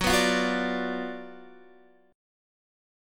GbM7sus4#5 chord